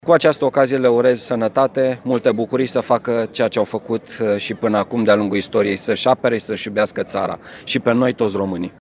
De la eveniment nu a lipsit prefectul judeţului, care le-a transmis un mesaj jandarmilor. Prefectul Braşovului, Marian Rasaliu:
jandarmi-Marian-Rasaliu.mp3